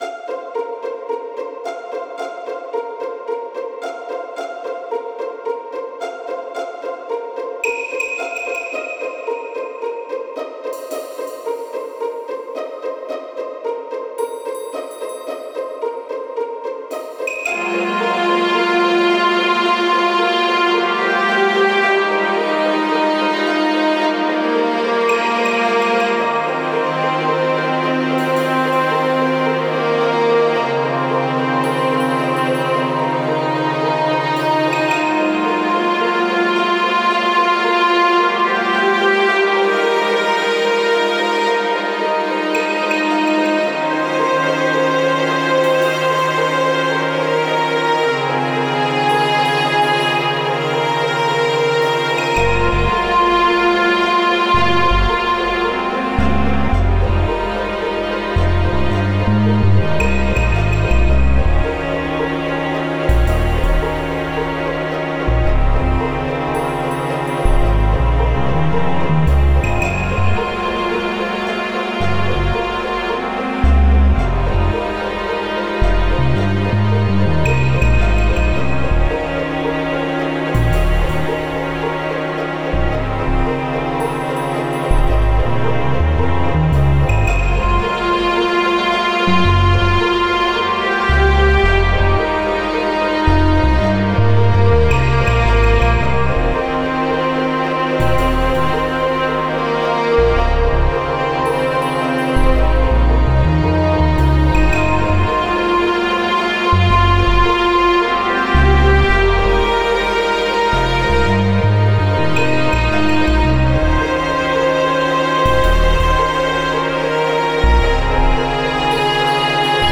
弦楽器とシンセベースで厳しくも幻想的な雰囲気を出しました